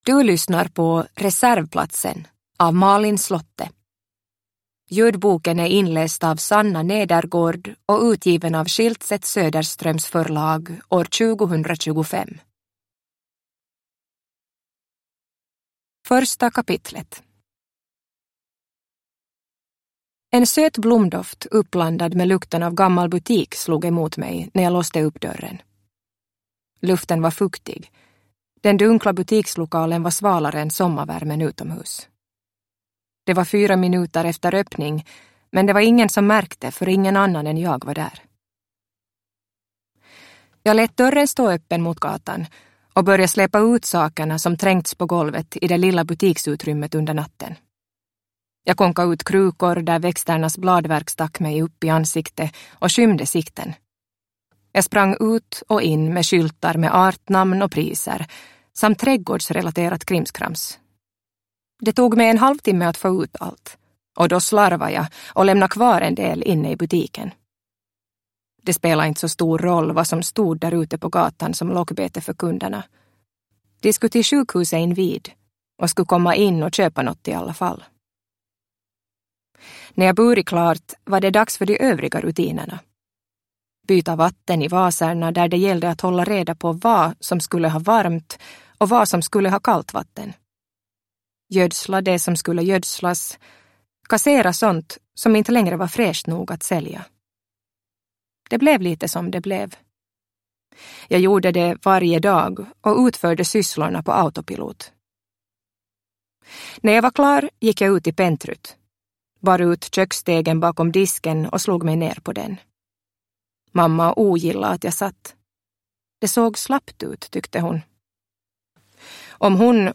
Reservplatsen (ljudbok) av Malin Slotte